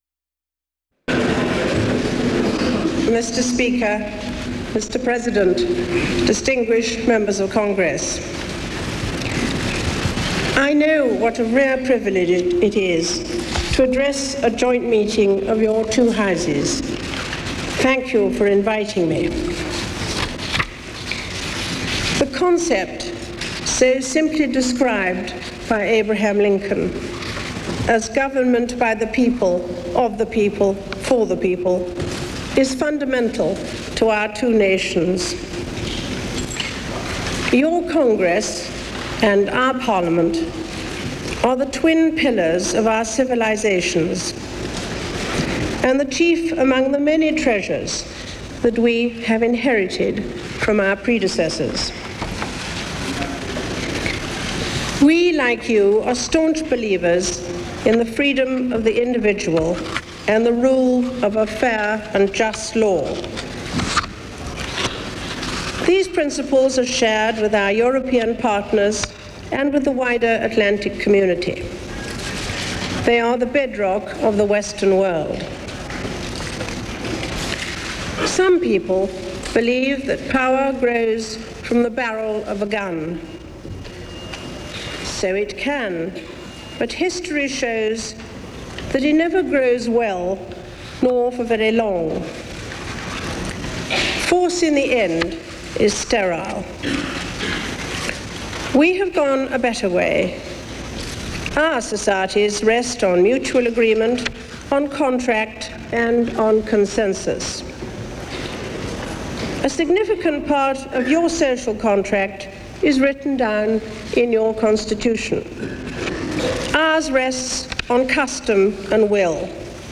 Queen Elizabeth addresses a joint session of the United States Congress on the subject of Anglo-American unity in a changing and sometimes hostile world